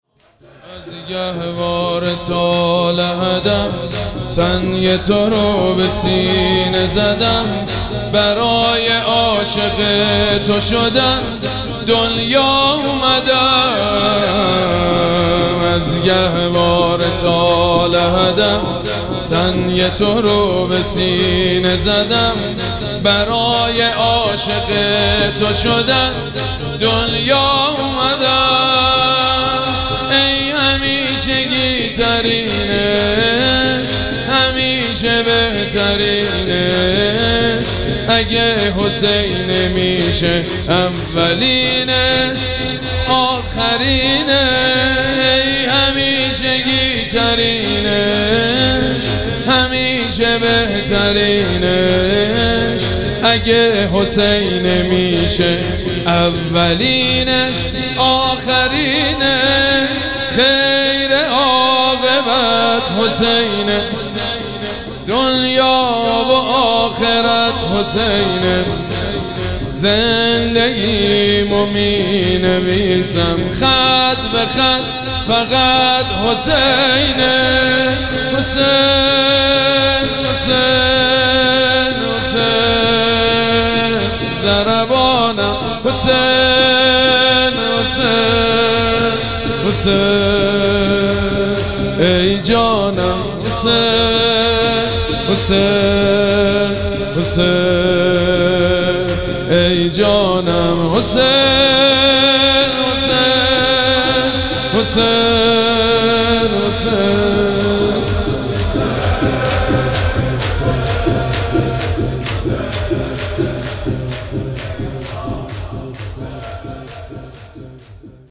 مراسم عزاداری شب شهادت امام حسن مجتبی(ع)
شور
حاج سید مجید بنی فاطمه